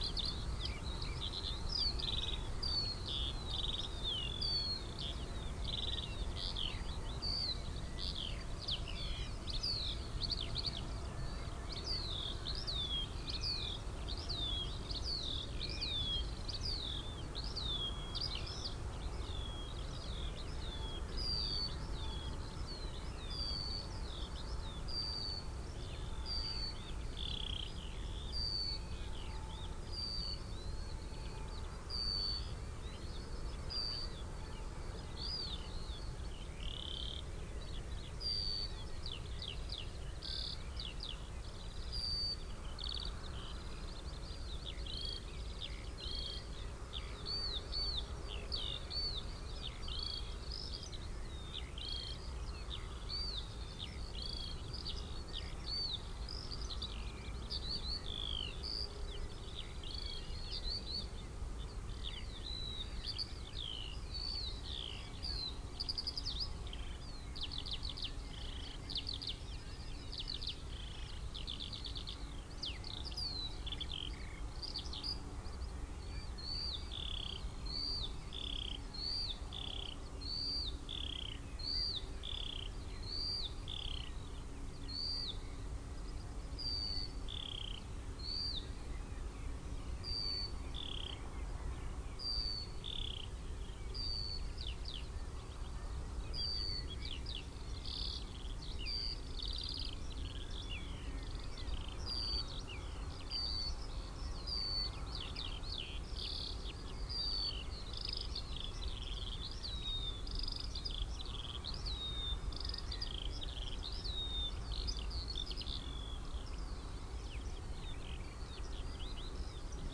Gallus gallus domesticus
Turdus merula
Passer domesticus
Alauda arvensis
Emberiza citrinella
Cyanistes caeruleus